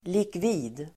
Uttal: [likv'i:d]